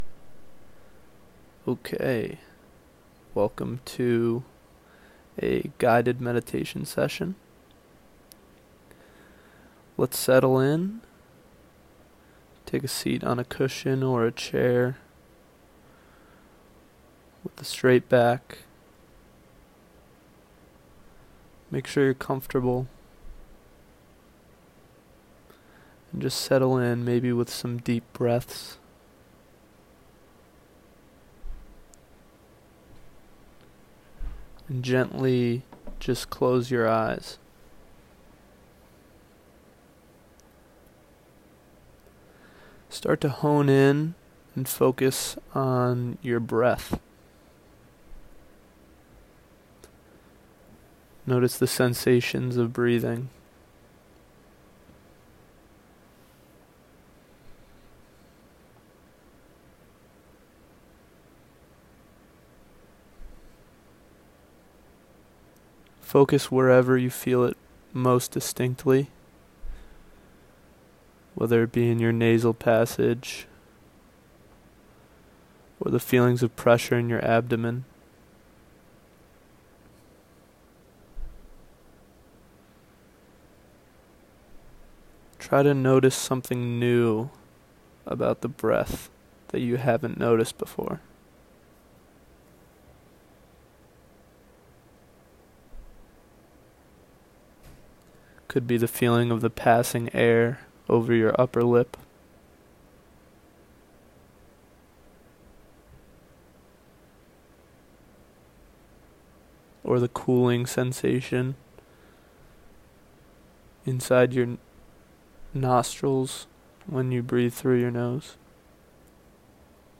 With that said, I have recorded a 14 minute session that I hope can help introduce you to meditation in a productive way.